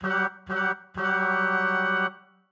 Index of /musicradar/gangster-sting-samples/95bpm Loops
GS_MuteHorn_95-G1.wav